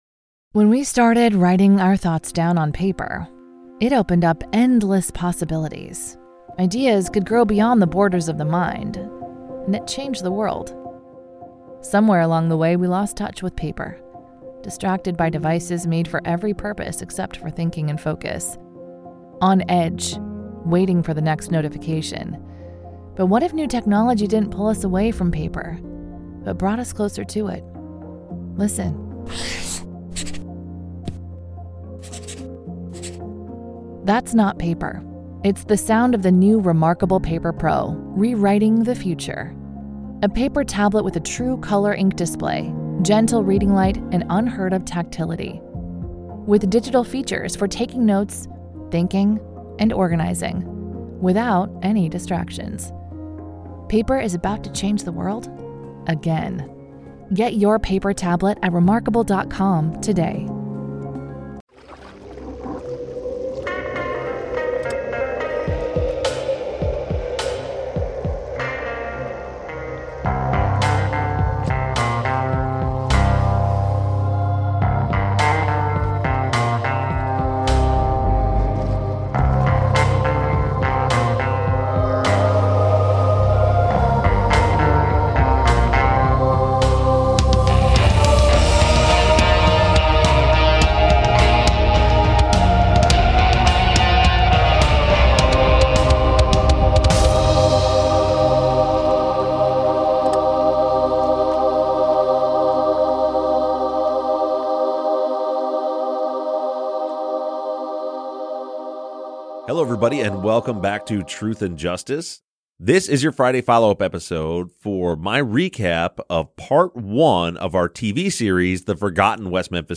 The guys answer listener questions remotely after Sunday's episode